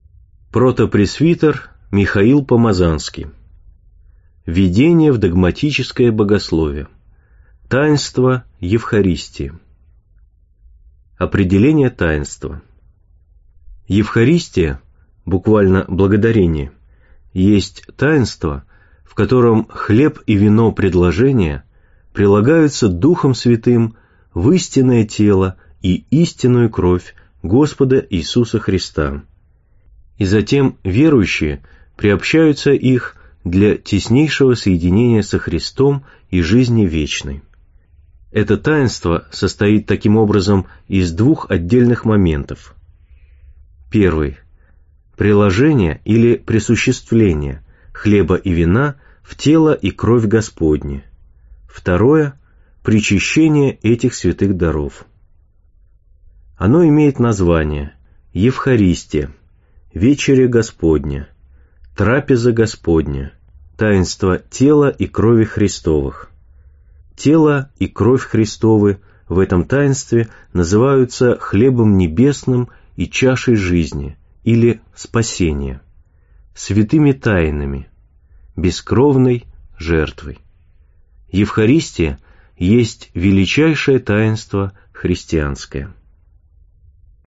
Аудиокнига В Единении со Христом: Таинство Евхаристии. Чудо венчания, или таинство божественной любви | Библиотека аудиокниг